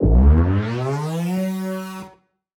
Index of /musicradar/future-rave-samples/Siren-Horn Type Hits/Ramp Up
FR_SirHornE[up]-G.wav